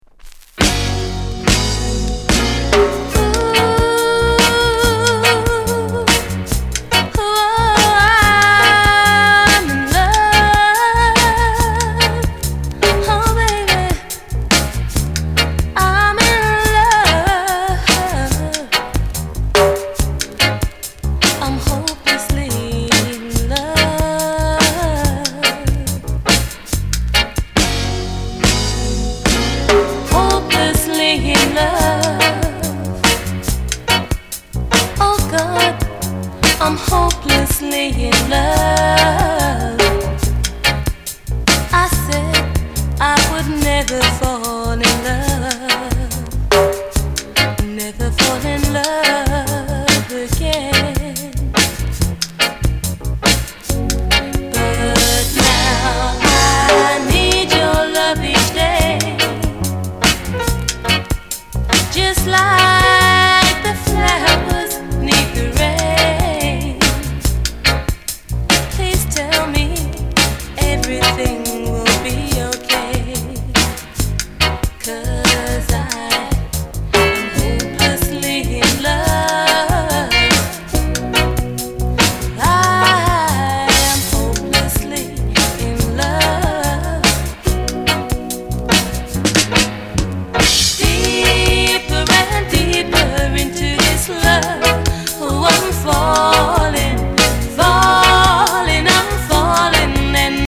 Genre: Reggae/ Lovers Rock